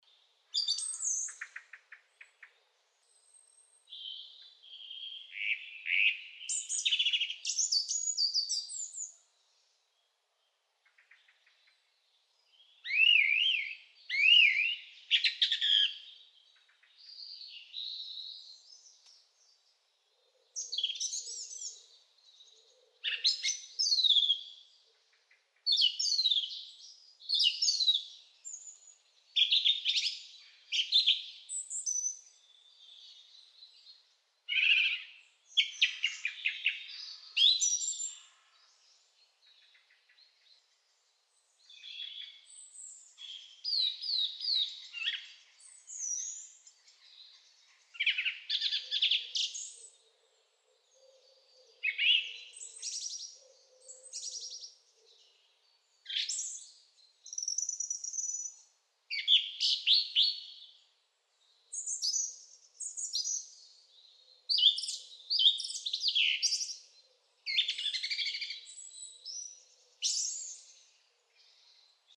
Звуки птиц
На этой странице собраны разнообразные звуки птиц: от щебетания воробьев до трелей соловья.